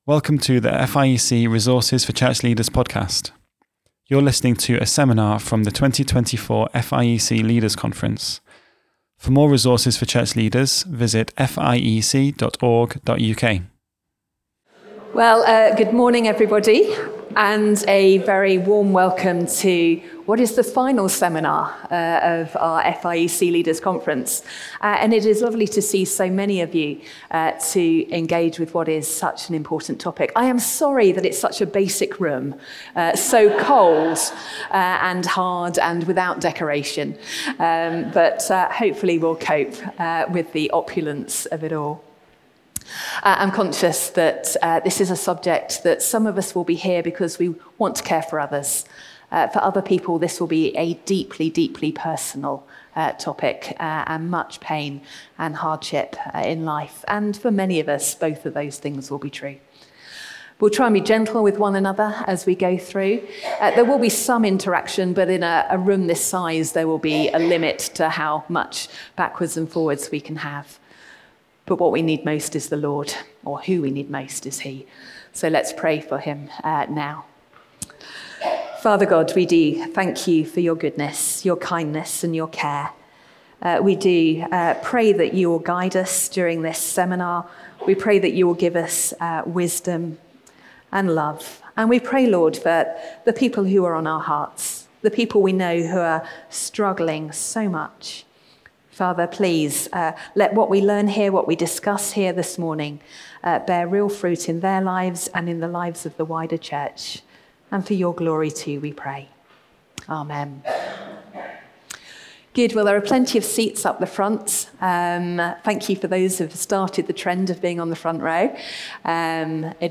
What can we do to help those struggling with mental health in ways that are loving, biblical, and wise? A seminar from the 2024 Leaders' Conference.